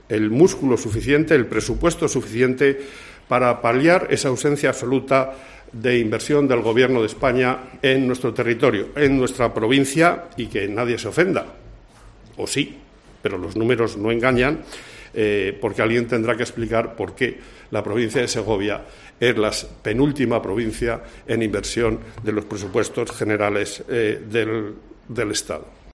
Miguel Ángel de Vicente, presidente de la Diputación Provincial, sobre la inversión para 2024